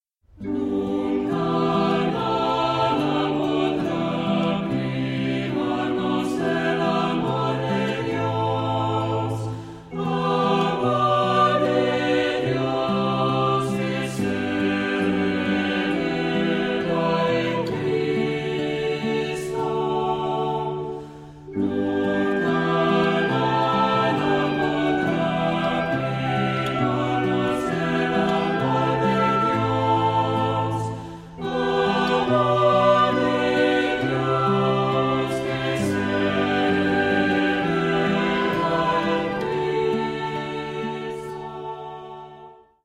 Voicing: Unison to SATB